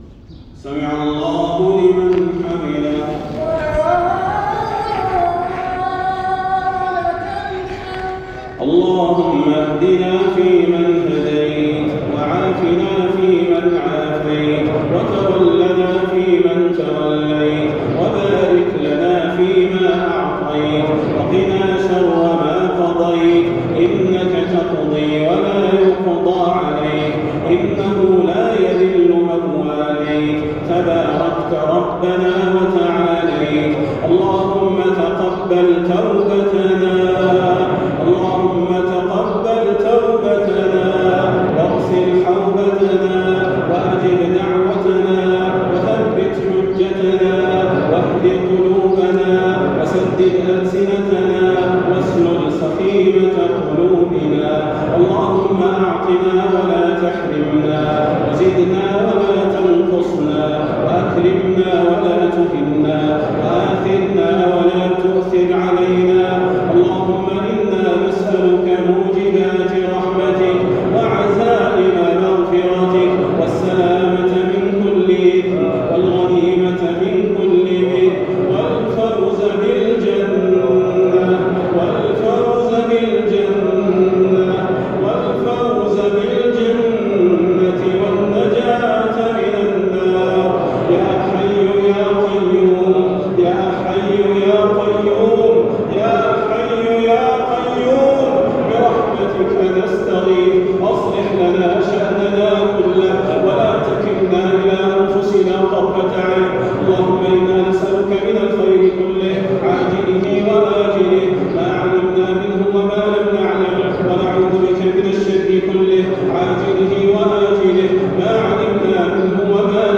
قنوت مؤثر للشيخ صلاح البدير ليلة ٢٧ رمضان ١٤٤٣هـ (تسجيل من المسجد)